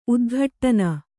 ♪ udghaṭṭana